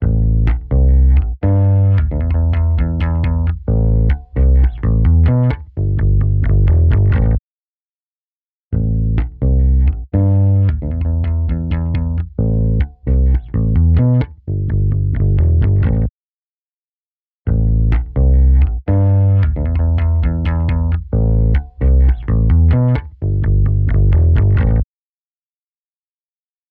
Invigorate | Bass | Preset: Bass Crisper
Invigorate-Bass-Bass-Crisper-CB.mp3